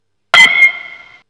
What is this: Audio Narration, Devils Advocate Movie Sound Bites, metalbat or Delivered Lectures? metalbat